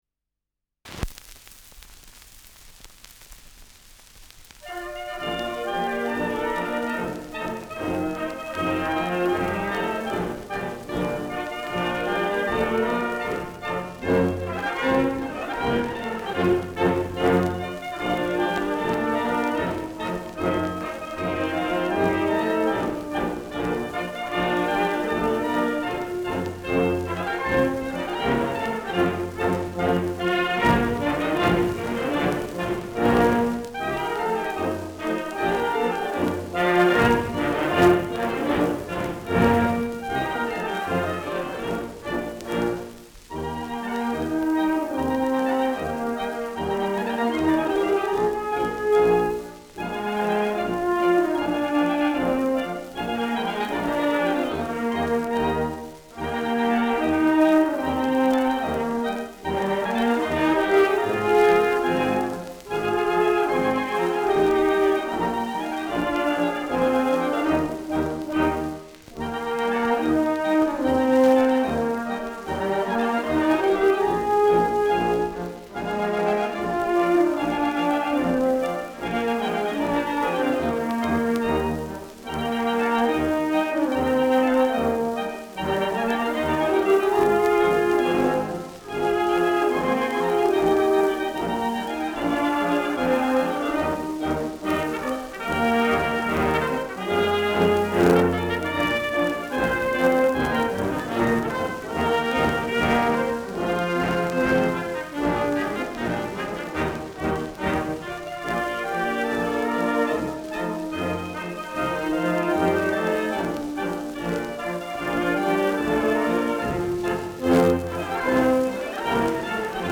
Schellackplatte
Deutlich abgespielt : Gelegentliches Knacken : Zischen an lauten Stellen
[München] (Aufnahmeort)